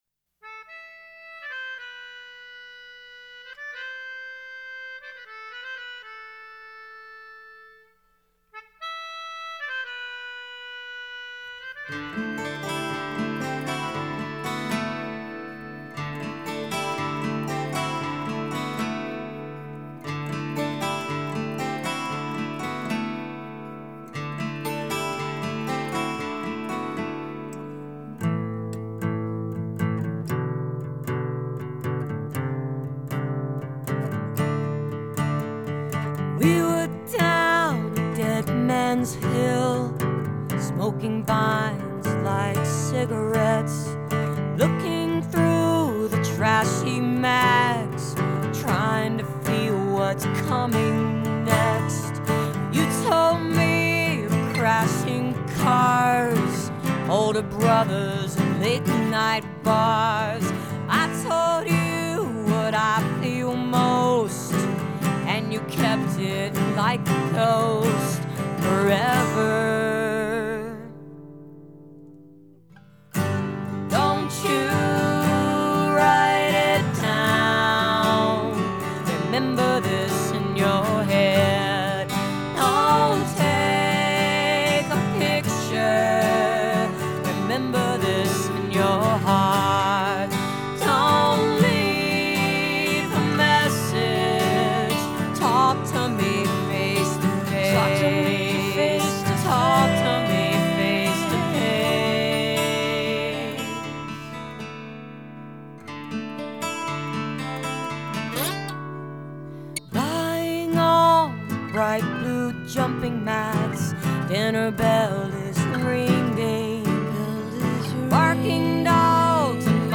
acoustic mix